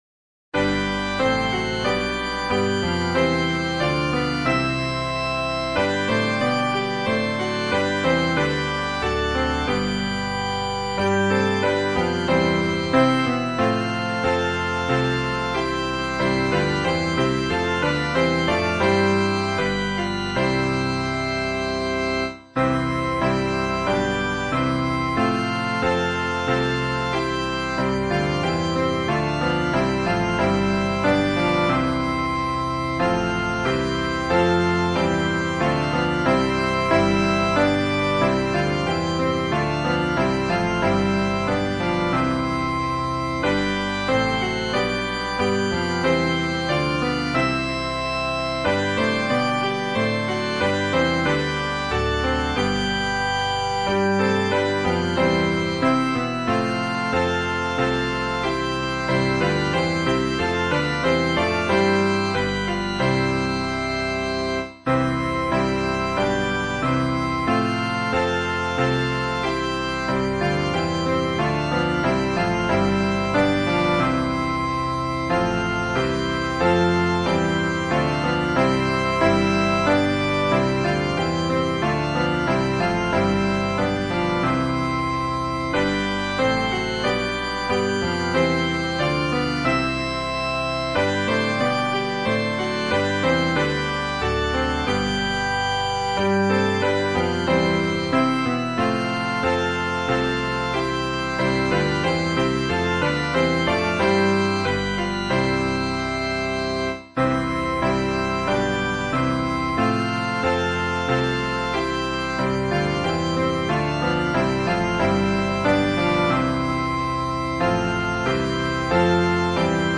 原唱